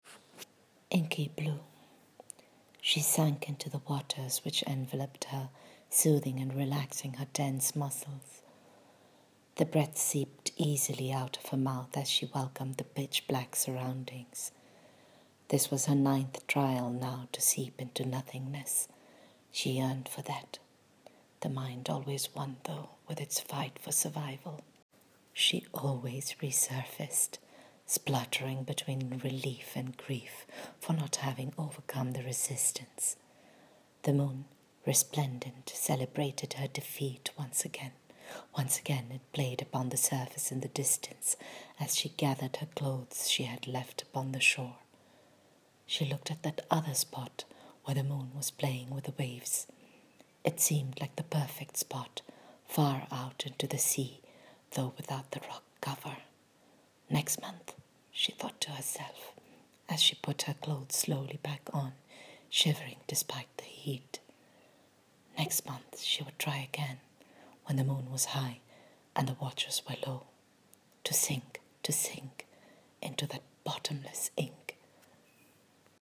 Reading of the microfiction: